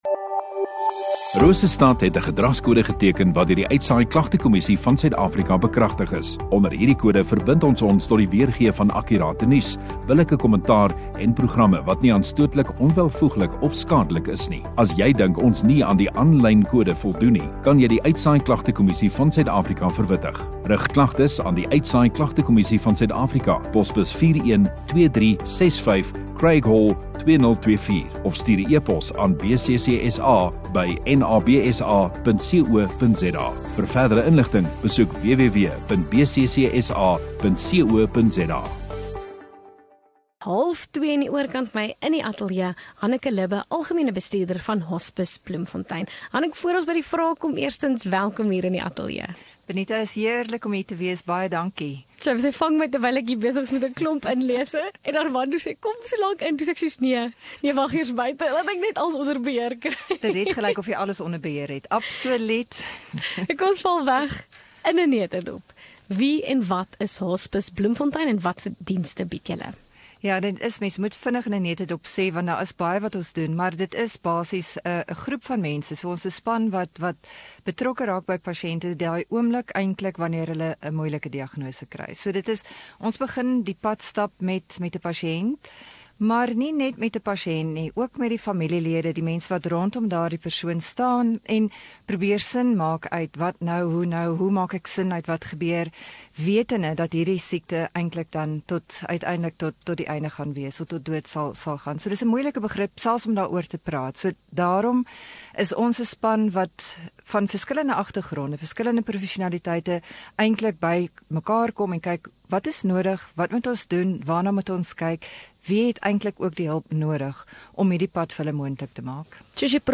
Radio Rosestad View Promo Continue Radio Rosestad Install Gemeenskap Onderhoude 5 Feb Hospice Bloemfontein